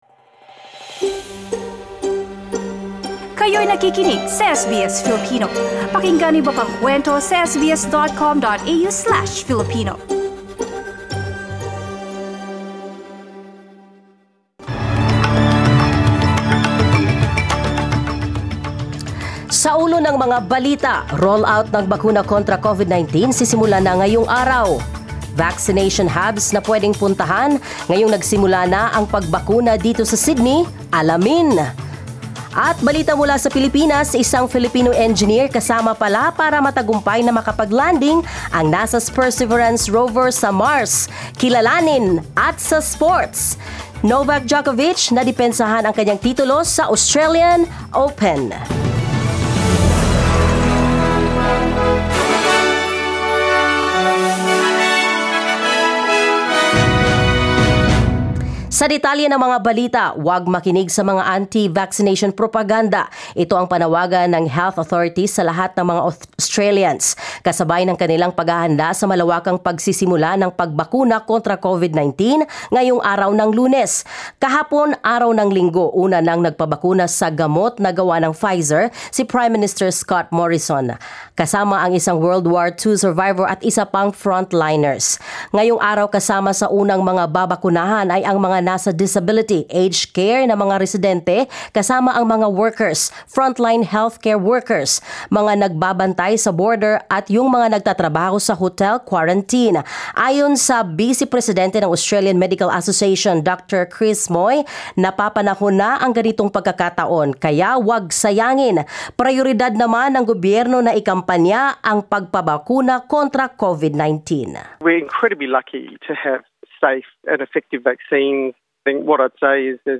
SBS News in Filipino, Monday 22 February